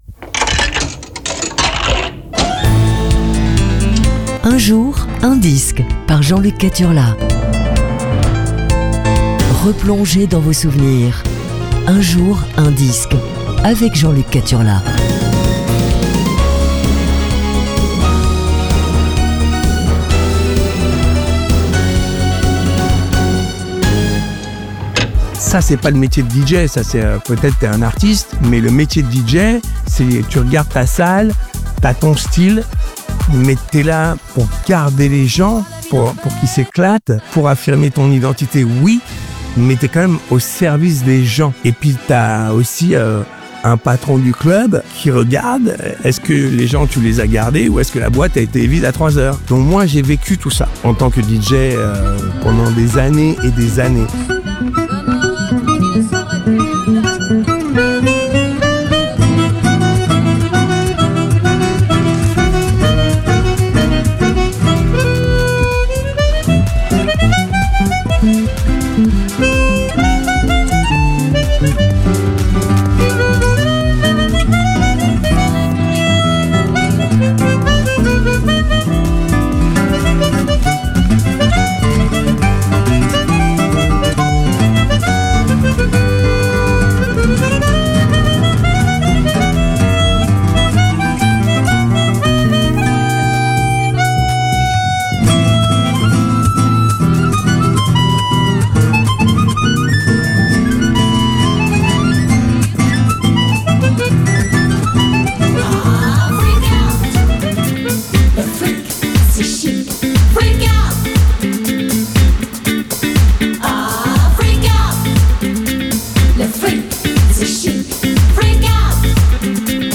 Aujourd'hui c'est Disco Funk - 3